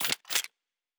Weapon 03 Reload 3.wav